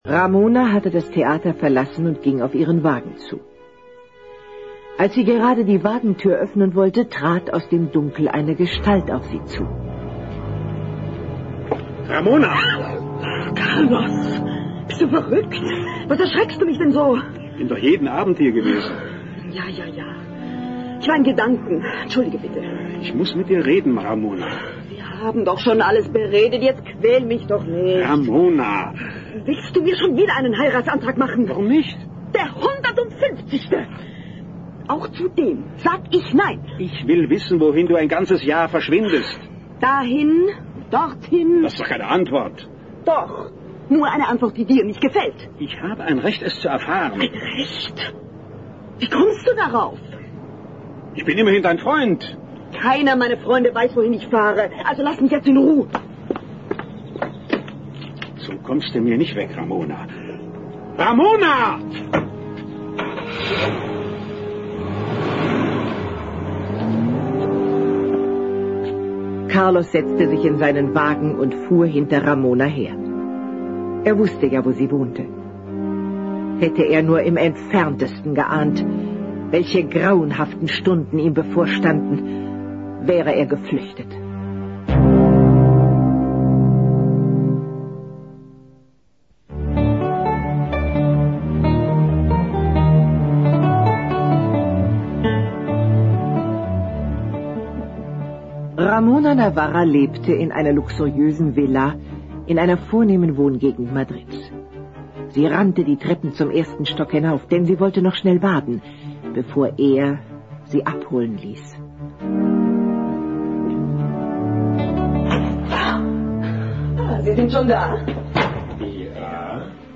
Das Hörspiel punktet mit durchgehender Spannung, dichter Atmosphäre und einer erstaunlich vielschichtigen Geschichte.